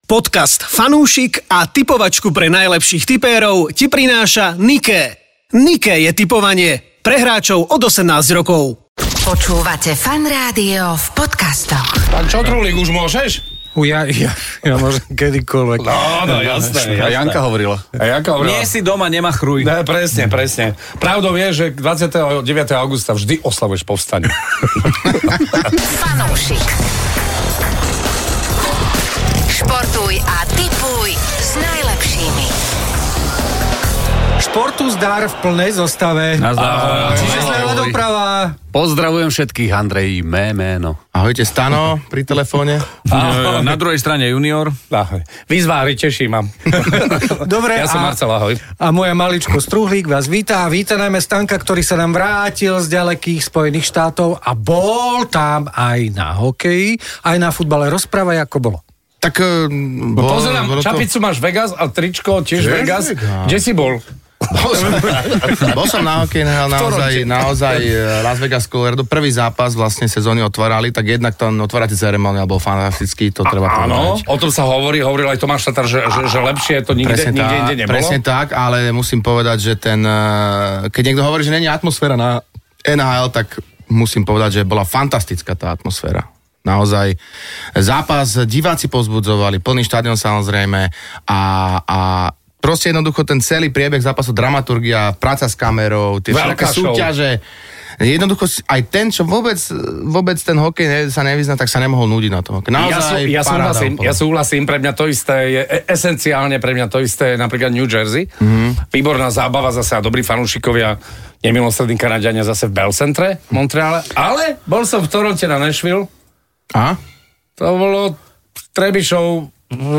Podcasty FUNúšik 85 LOS ANGELES JE AKO ŠTVORMILIÓNOVÝ VRANOV 22. októbra 2024 V tejto časti aj: zážitky z NHL, slovenská boxerská nádej, Liga majstrov aj Juraj Slafkovský. Daj si fanúšikovskú debatku o športe a tipovaní.